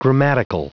Prononciation du mot grammatical en anglais (fichier audio)
Prononciation du mot : grammatical